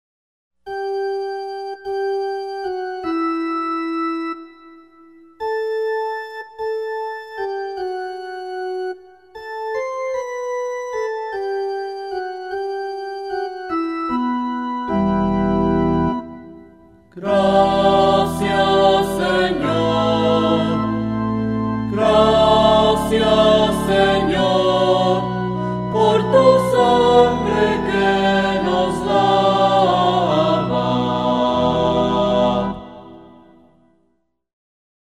SALMO RESPONSORIAL Del salmo 115 R. Gracias, Señor, por tu sangre que nos lava.